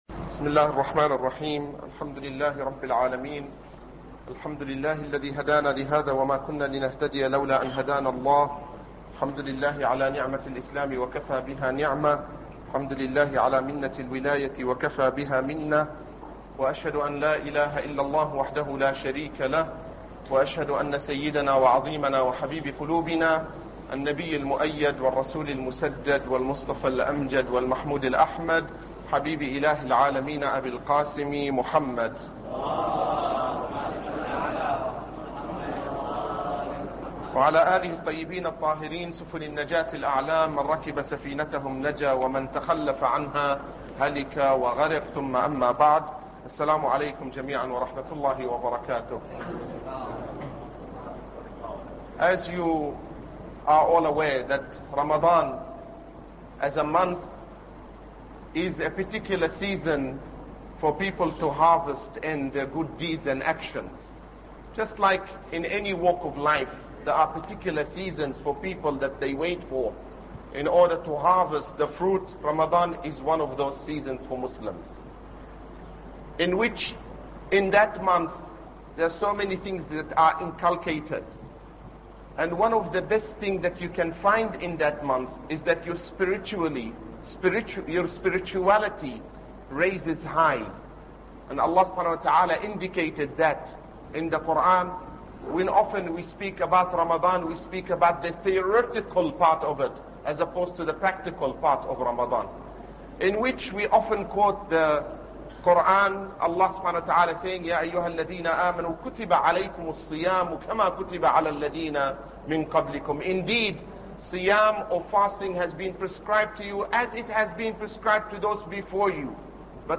Ramadan Lecture 1